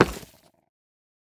Minecraft Version Minecraft Version 1.21.5 Latest Release | Latest Snapshot 1.21.5 / assets / minecraft / sounds / block / nether_ore / step3.ogg Compare With Compare With Latest Release | Latest Snapshot
step3.ogg